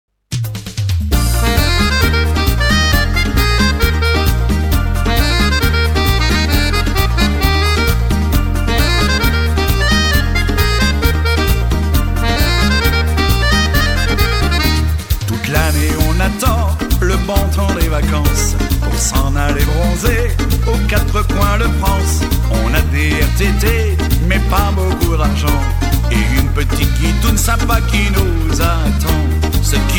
Genre : zouk.